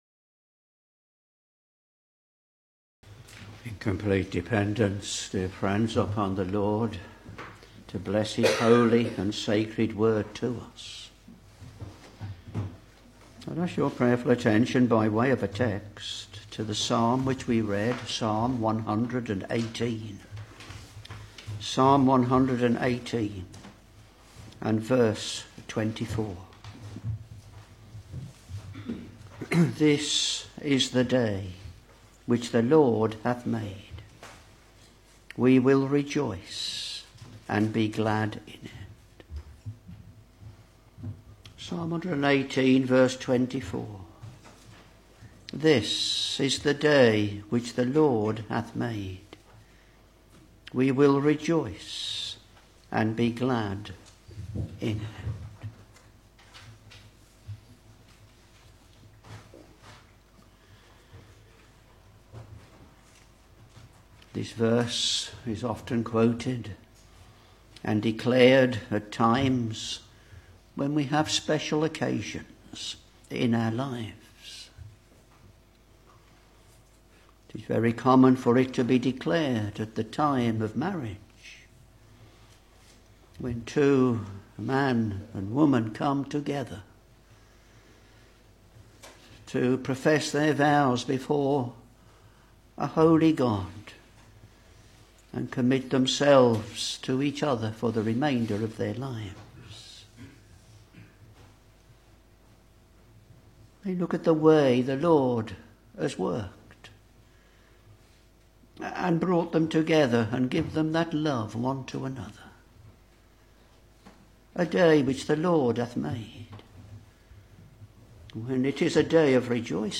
Sermons Psalm 118 v.24 This is the day which the LORD hath made; we will rejoice and be glad in it.